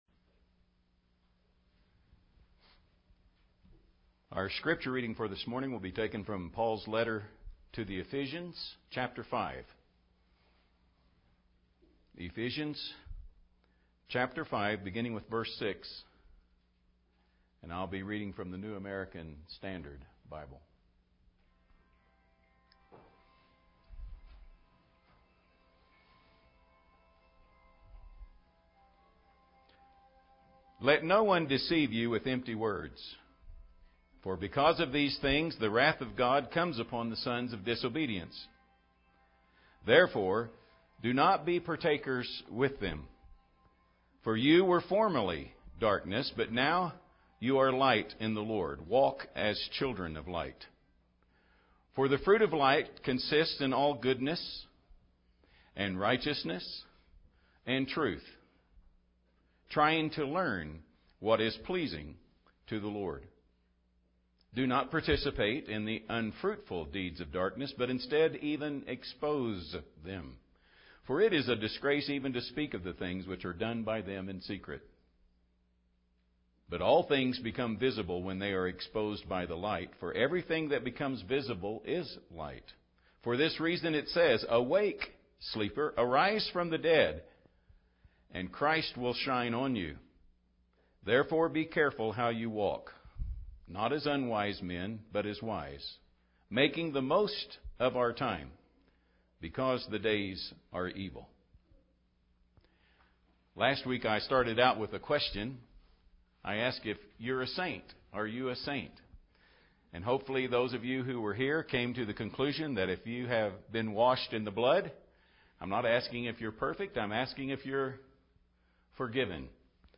← Newer Sermon Older Sermon →